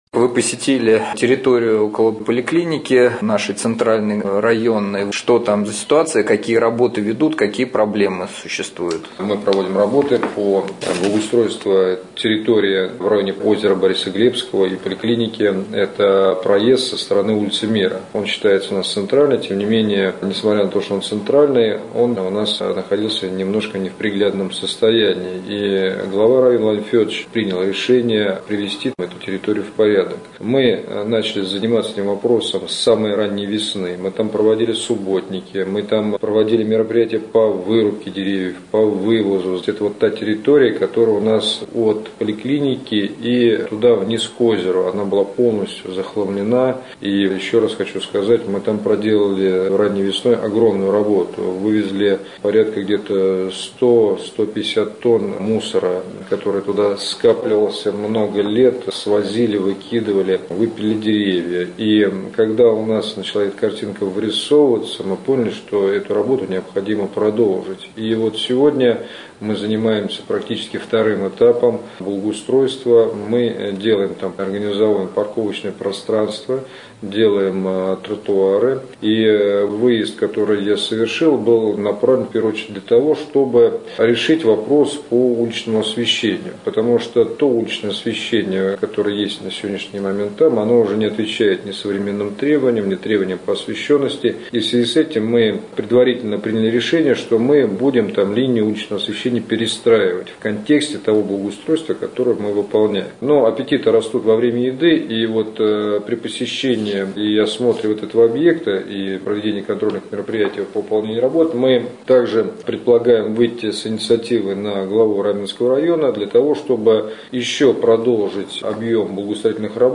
4. В студии Виталий Чехов